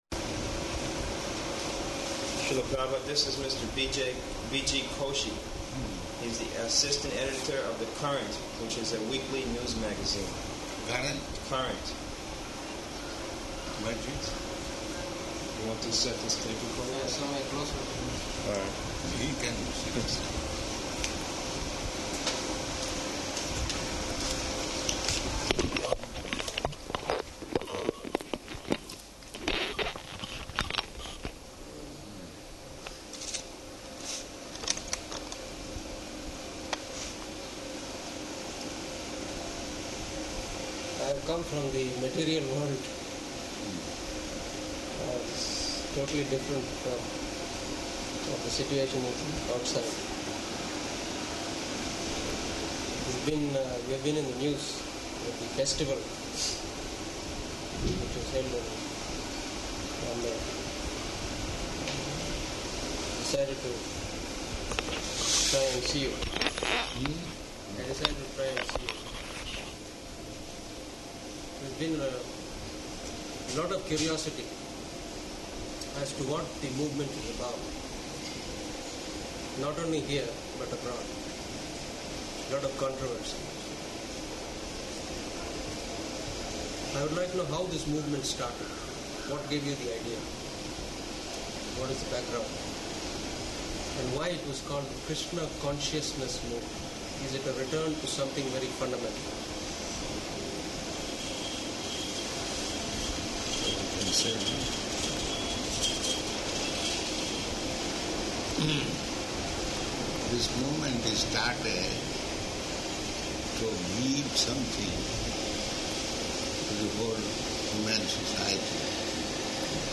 -- Type: Interview Dated: April 5th 1977 Location: Bombay Audio file